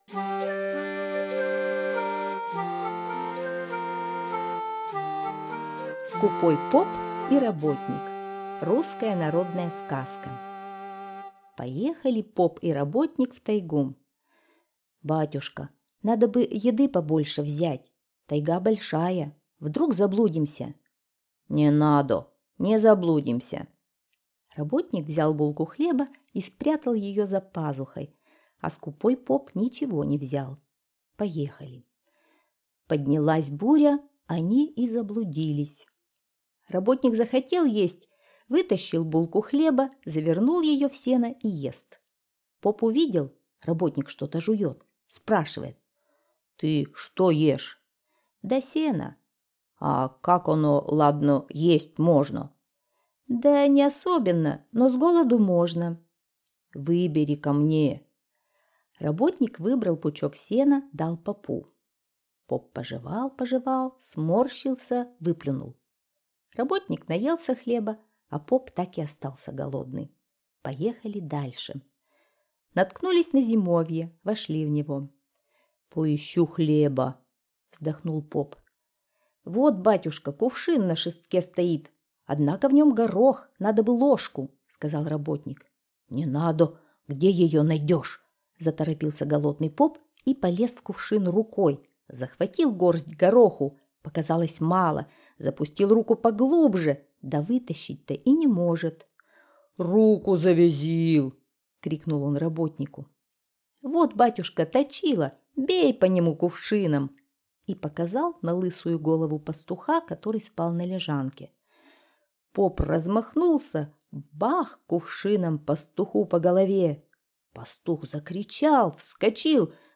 Скупой поп и работник - русская народная аудиосказка - слушать онлайн